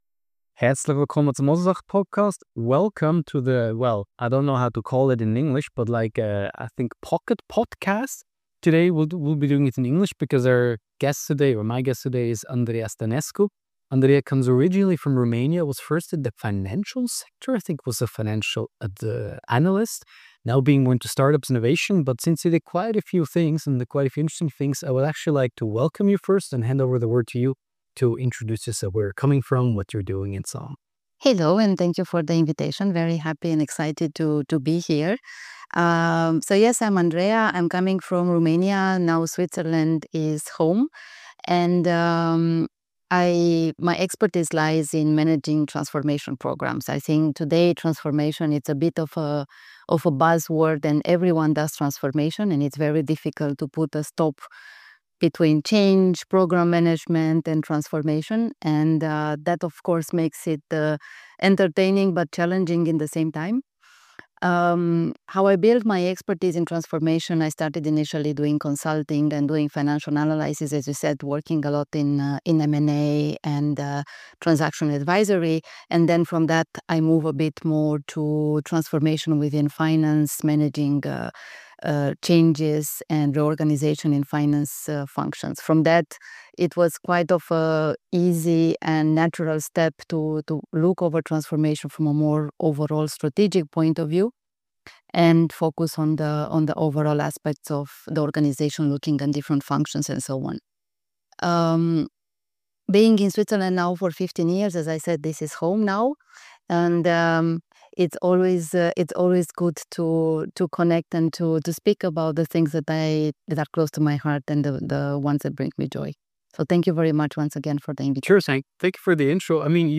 A sharp, honest conversation with someone who knows how to drive change – beyond the buzzwords.